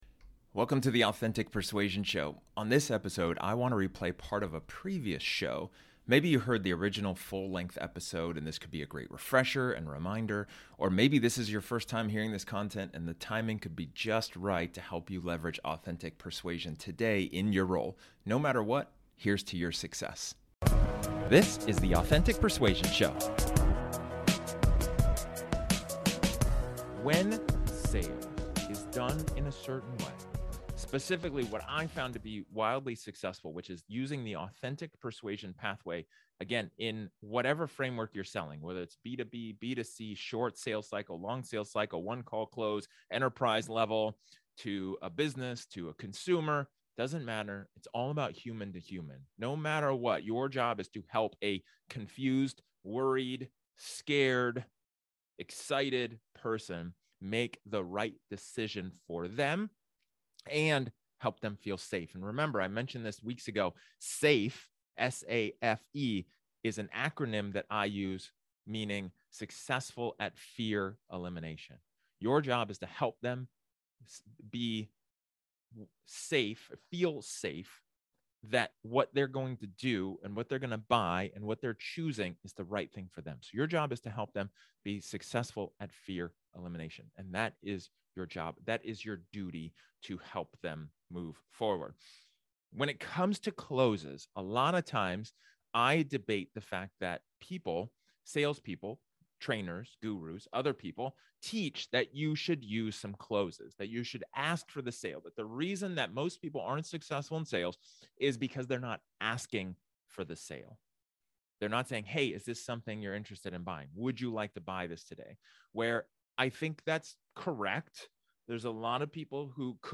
This is an excerpt from one of my training sessions where I talked about some important closes that you should be using in sales.